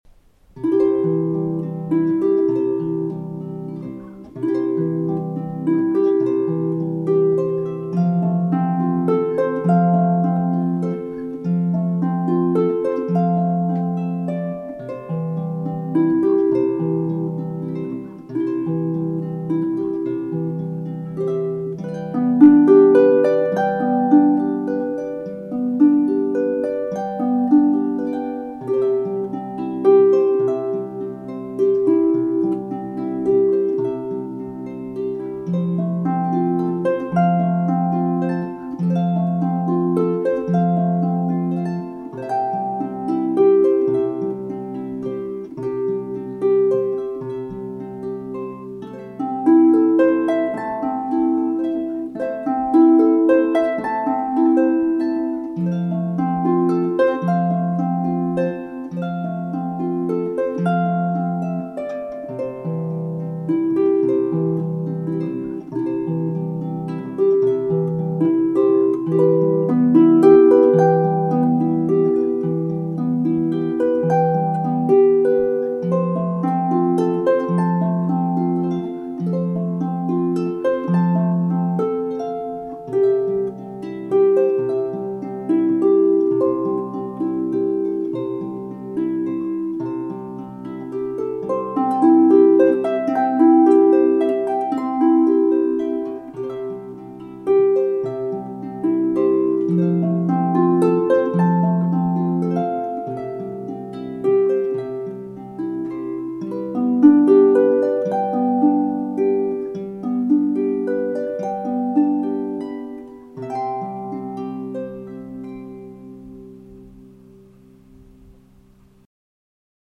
Harp music